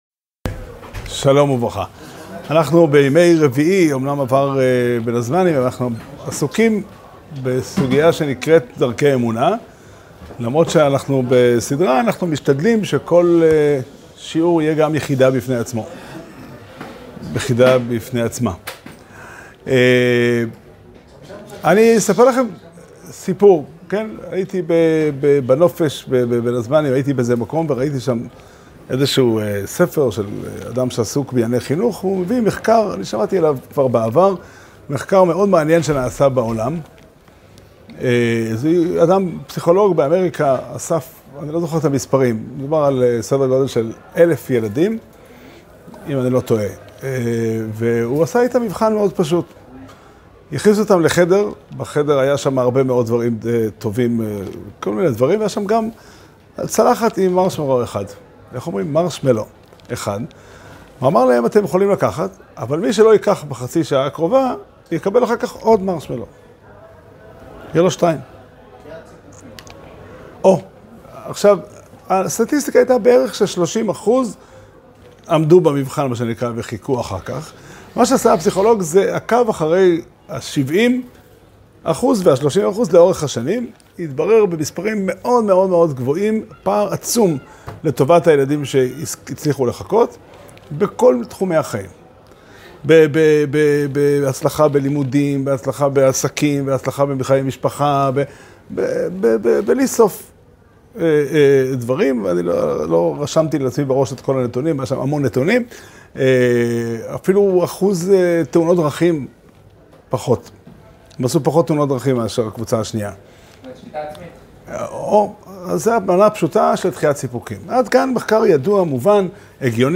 שיעור שנמסר בבית המדרש פתחי עולם בתאריך ב' אלול תשפ"ד